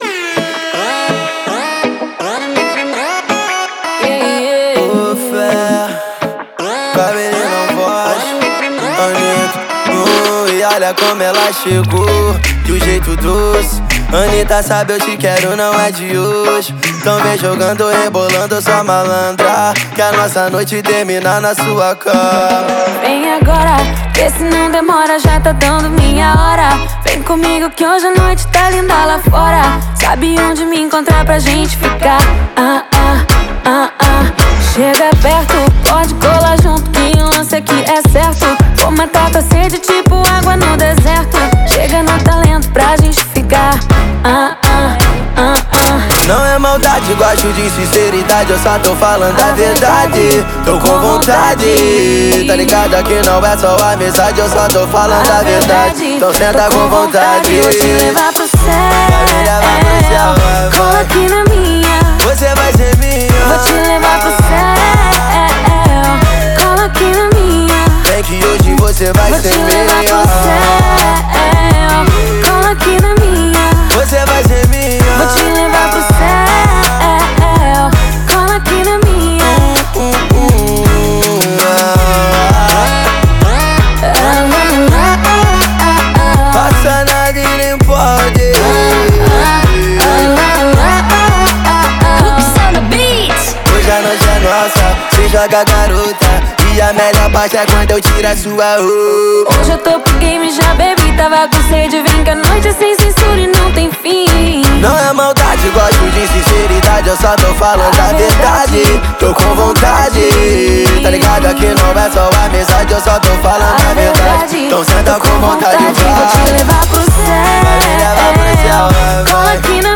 энергичная бразильская песня в жанре фанк